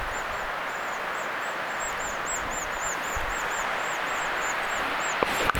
muuttava hömötiaisparvi saaressa
muuttava_homotiaisparvi.mp3